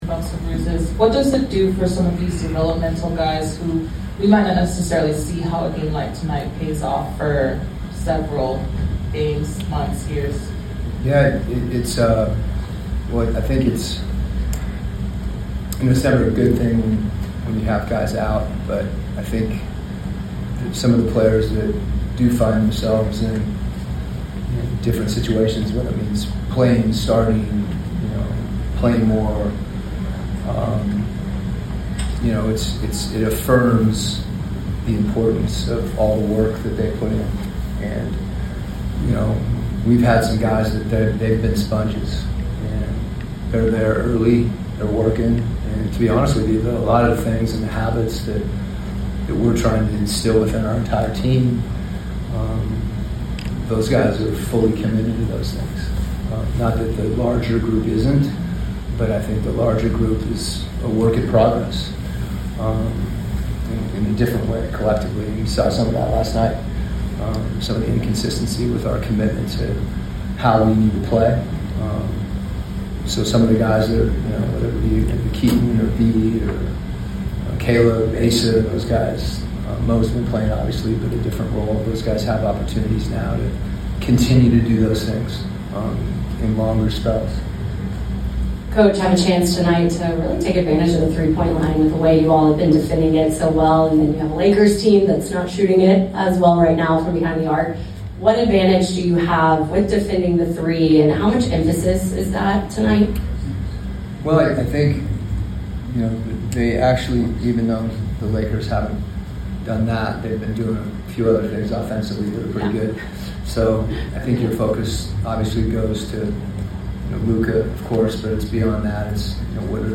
Atlanta Hawks Coach Quin Snyder Pregame Interview before taking on the Los Angeles Lakers at State Farm Arena.